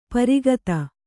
♪ pari gata